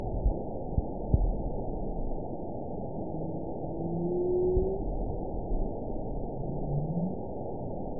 event 916209 date 12/27/22 time 08:23:21 GMT (2 years, 11 months ago) score 9.13 location INACTIVE detected by nrw target species NRW annotations +NRW Spectrogram: Frequency (kHz) vs. Time (s) audio not available .wav